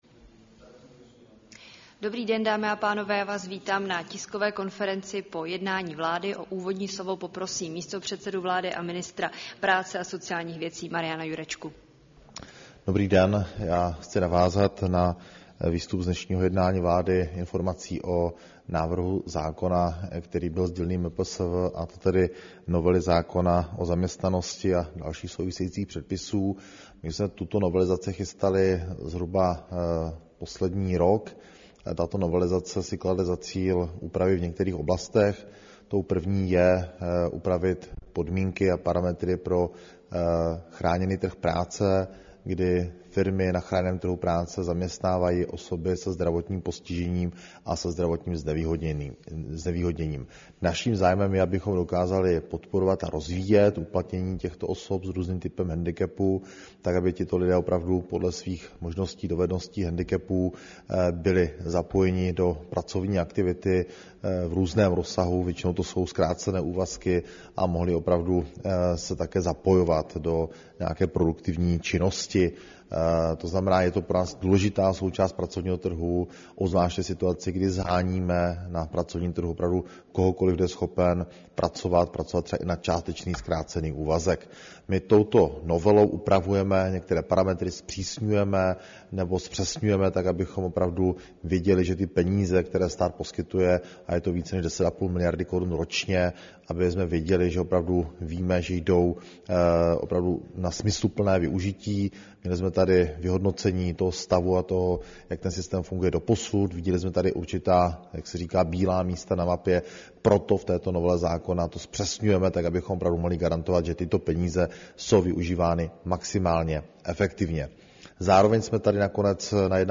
Tisková konference po jednání vlády, 3. července 2024